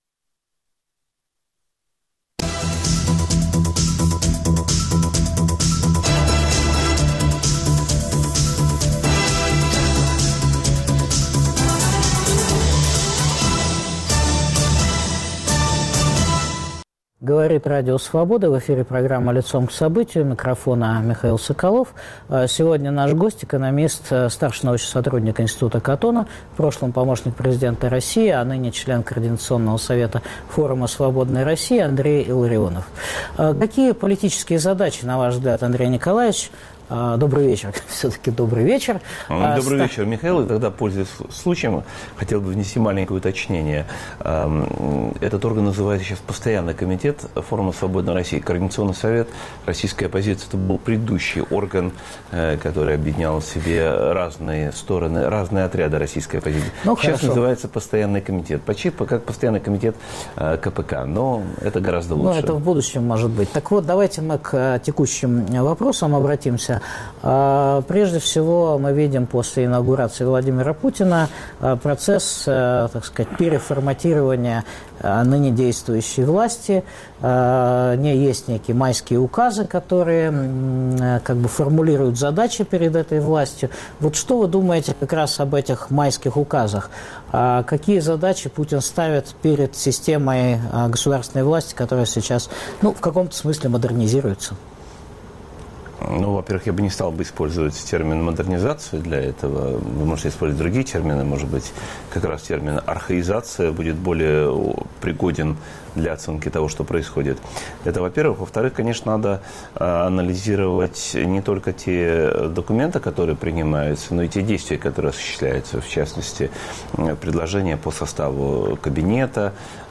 Что происходит с российской властью? Обсуждаем с экономистом, старшим научным сотрудником Института Катона Андреем Илларионовым.